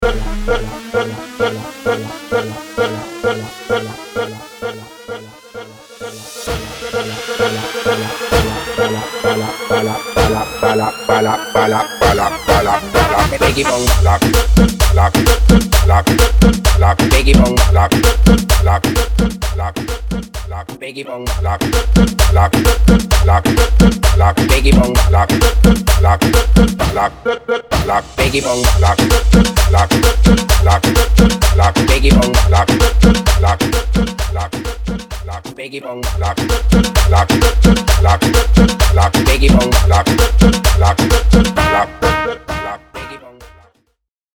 130 / Guaracha